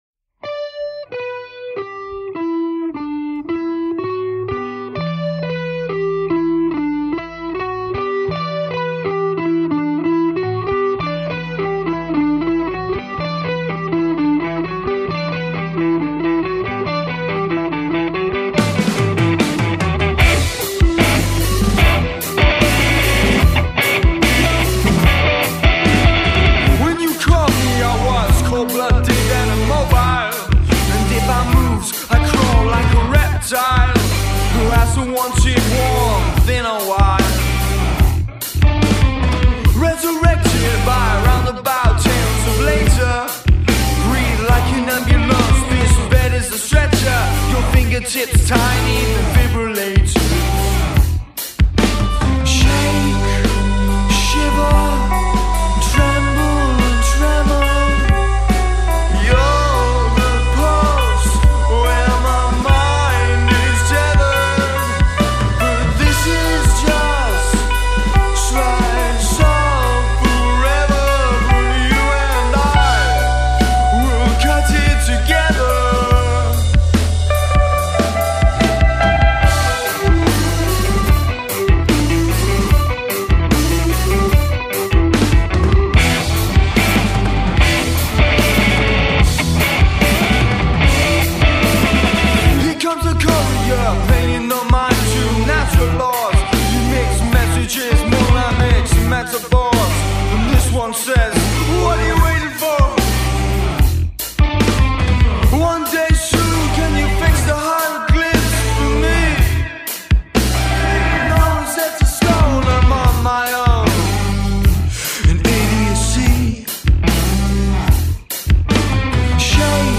four piece group